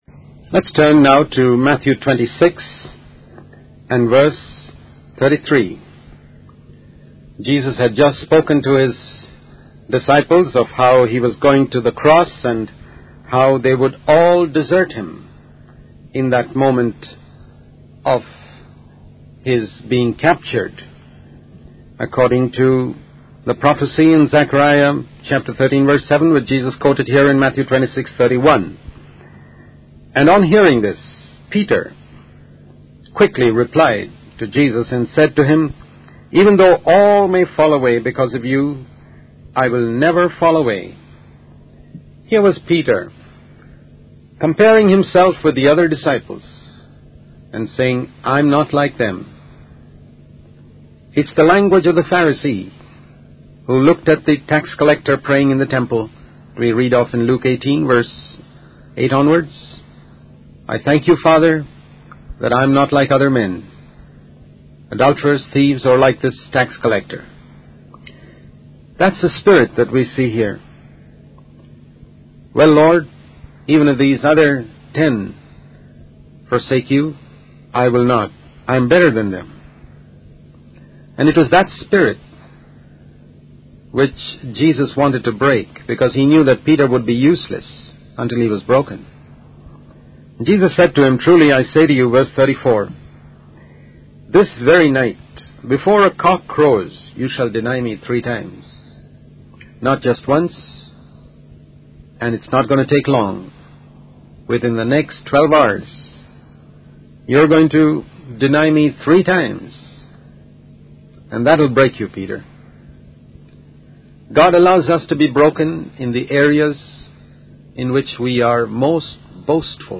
In this sermon, the speaker emphasizes the importance of watching and praying to avoid falling into temptation. He highlights Jesus' example of purity and warns against the dangers of betrayal. The speaker discusses the incident in the garden of Gethsemane, where Judas betrays Jesus with a kiss and Peter reacts by cutting off the ear of a slave.